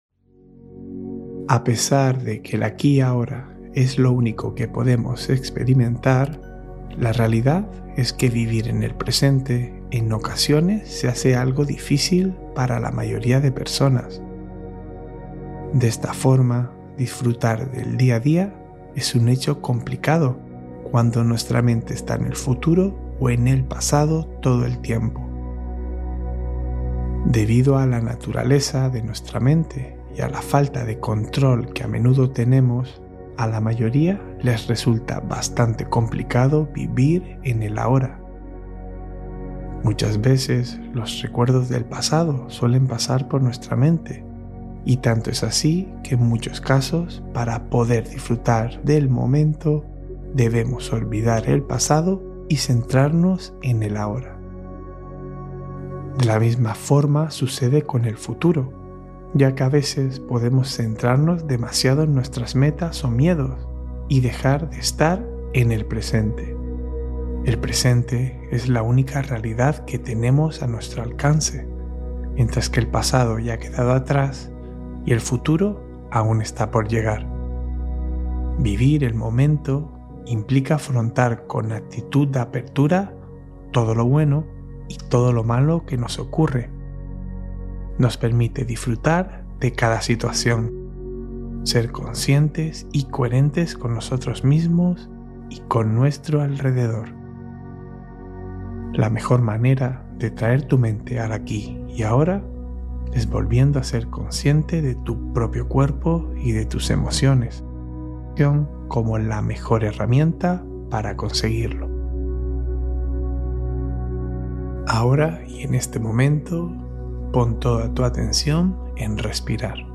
Relajación del Alma — Viaje Guiado para un Sueño Pleno